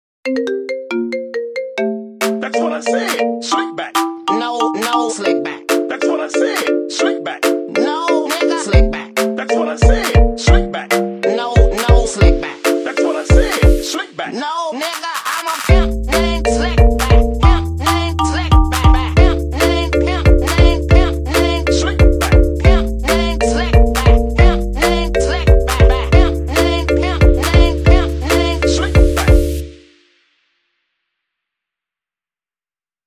Sonnerie Gratuite
Marimba Remix